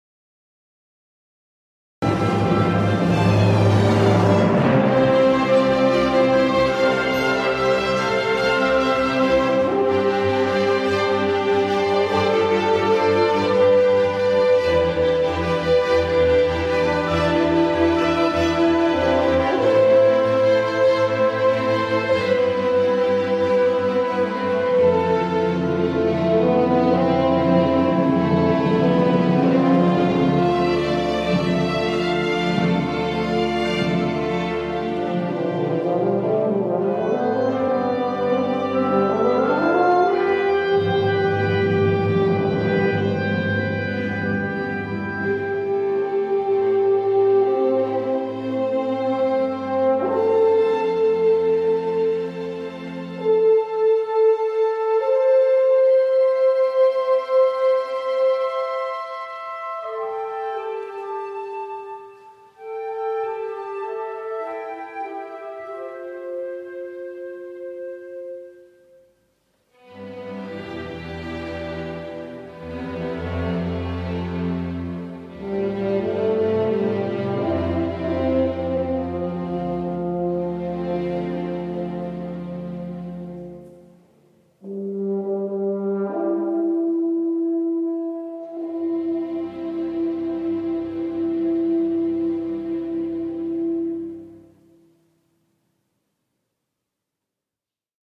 Concerto for Euphonium and Loopstation. Recorded live at Christ Church, Skipton on 8 November 2014